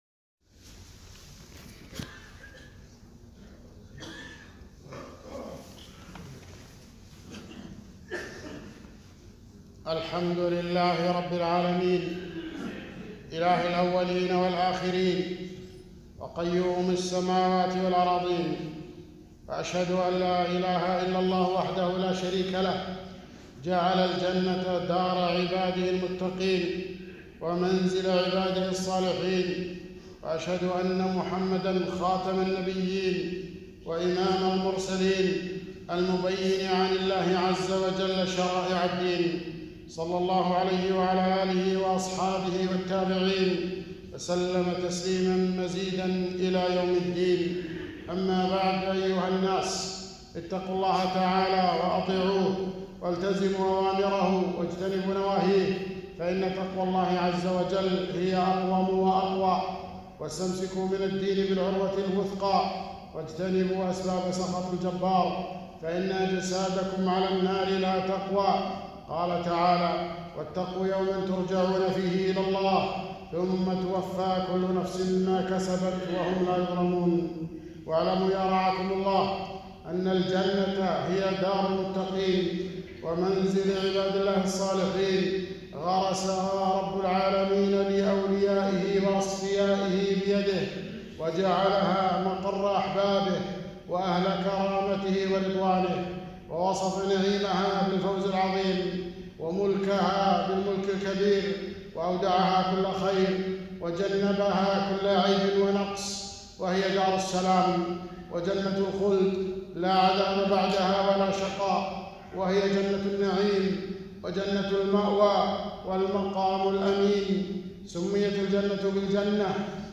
خطبة - دار المتقين